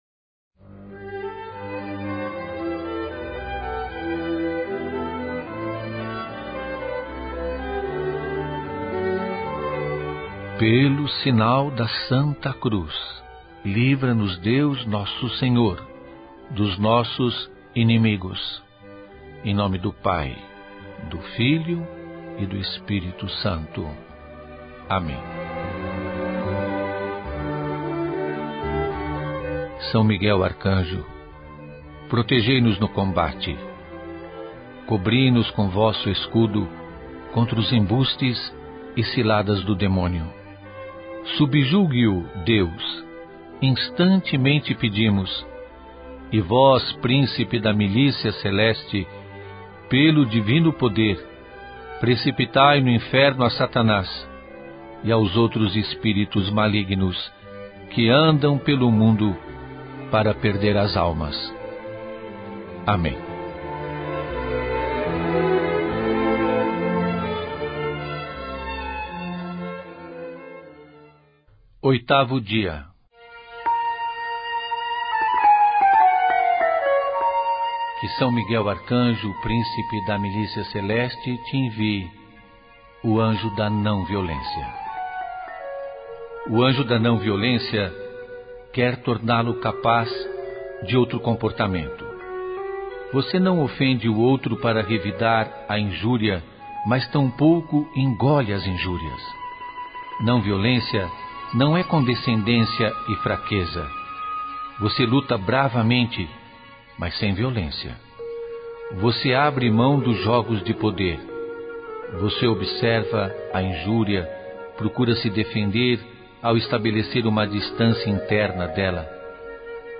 Julio Lancellotti. 8º dia: Esta novena foi produzida nos estúdios da Universidade São Judas Tadeu
Neste mês em que se celebra a festa do padroeiro, participe da Novena em honra a São Miguel Arcanjo, baseada no livro “50 Anjos para a Alma” do monge Anselm Grun, na voz do Pe. Julio Lancellotti.